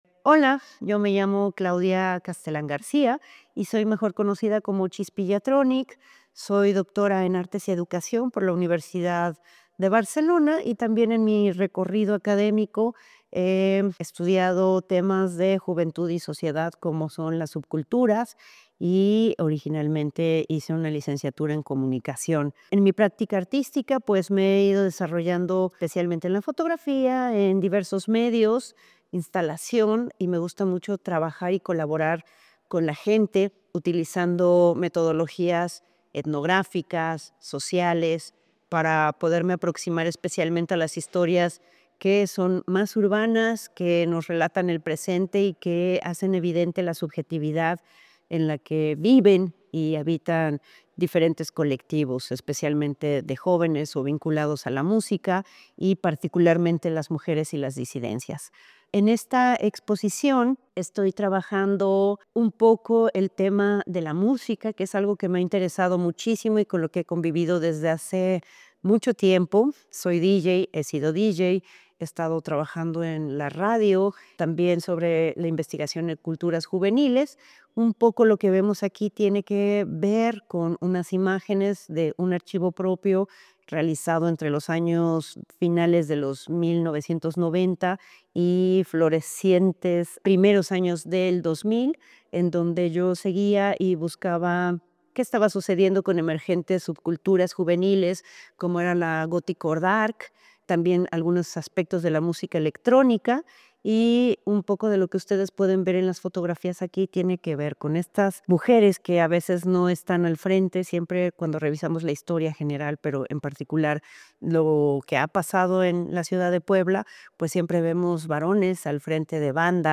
En la cabina y en los walkman, es posible escuchar audios que recolectan sus testimonios. Estas narraciones cercanas e íntimas, expuestas en dispositivos consonantes con el tiempo al cual se hace referencia, reflexionan, no sólo sobre el legado de la música alternativa y la individualización de la escucha en la época digital, también sobre la violencia de género, la expresión de una voz propia y la relación cambiante entre mujeres y ciudad a lo largo de los últimos treinta años.